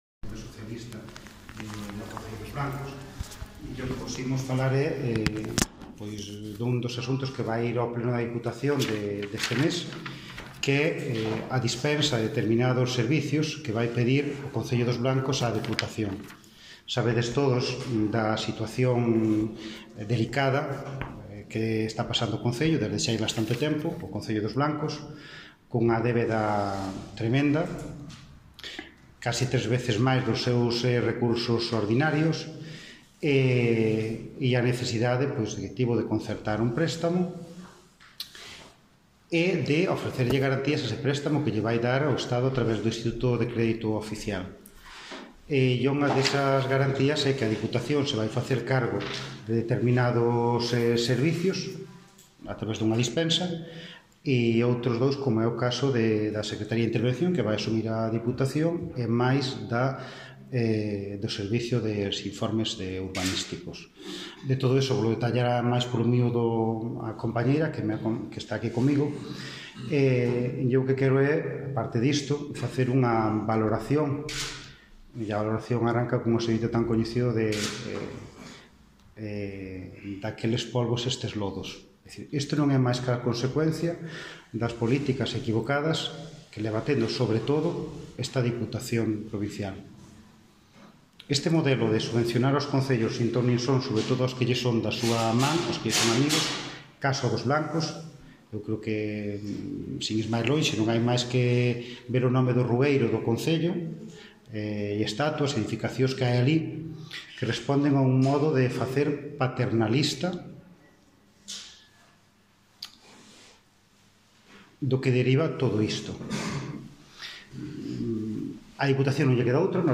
Audio da rolda de prensa